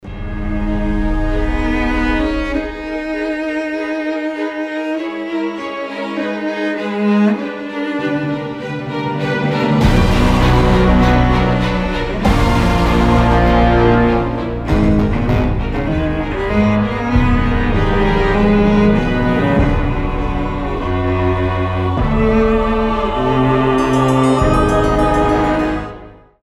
• Качество: 320, Stereo
без слов
виолончель
эпичные